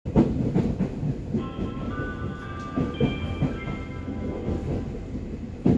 ・01形車内チャイム
駅到着時
熊電標準の車内チャイムを使用しています。